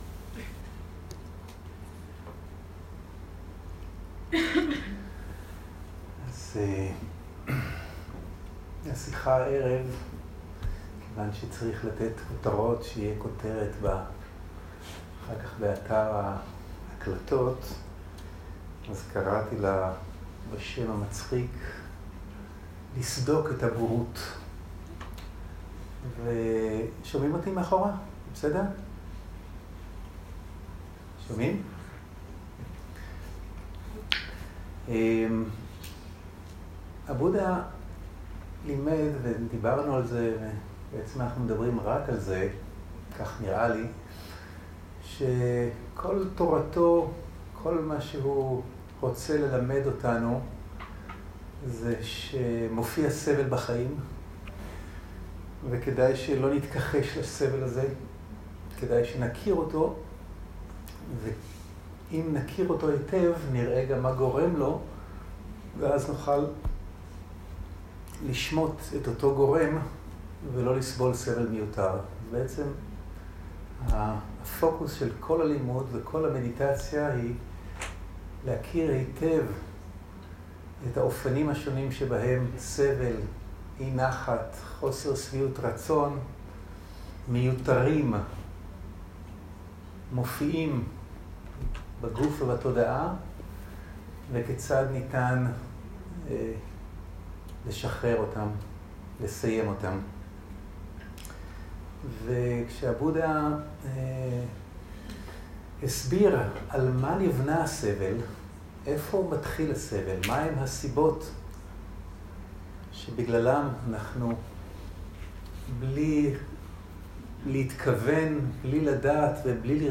שיחות דהרמה שפת ההקלטה